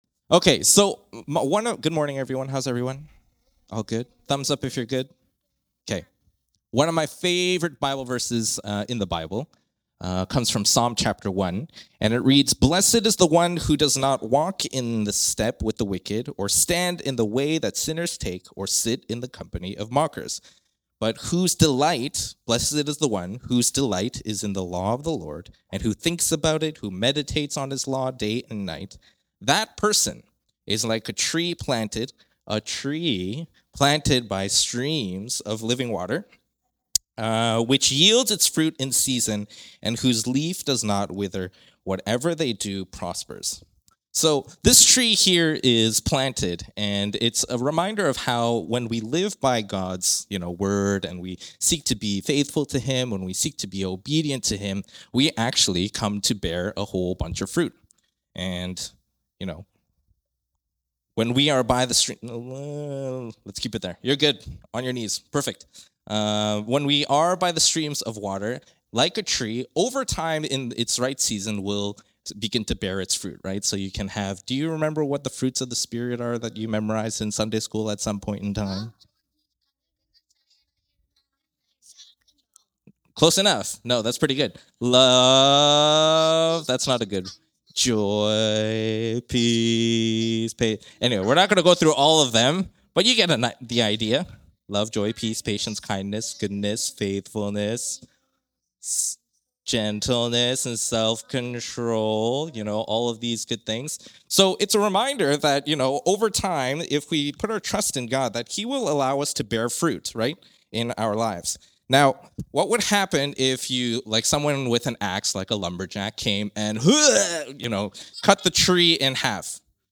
It is from the youth focus from the Sunday service on February 8, 2026.